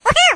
雷电精灵Electro Spirit最多可以一下击晕9个目标，语音很有电流感。